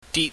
Cantonese Sounds-Mandarin Sounds
dit die || zhi || yi#
ditMT.mp3